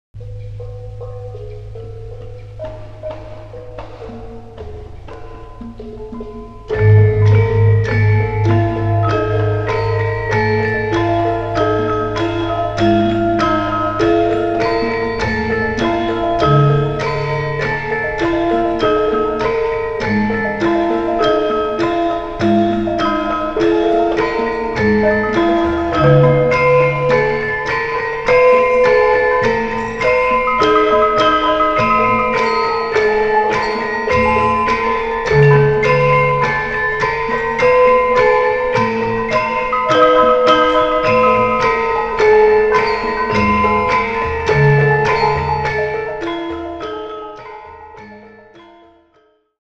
The recordings are very different; the court gamelan recording was done specifically to capture the sound of the gamelan while the UW gamelan recording was done to record the music of the concert.
Played by Kyai Telaga Muntjar, a Javanese court gamelan, on 10 January 1971.
The strong playing style is typically Jogyanese, with principal elaboration left to the bonangs.